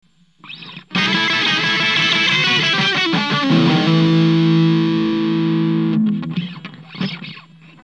humbucker bridge
TS9_humb_bridge.mp3